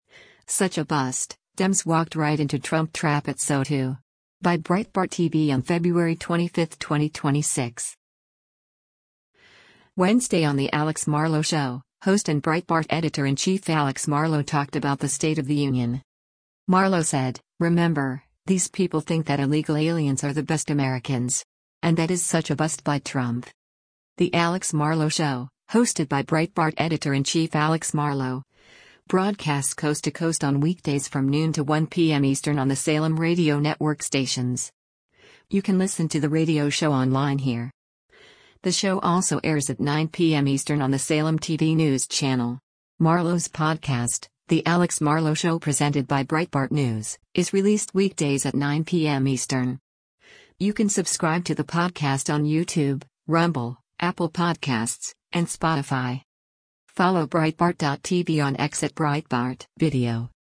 Wednesday on “The Alex Marlow Show,” host and Breitbart Editor-in-Chief Alex Marlow talked about the State of the Union.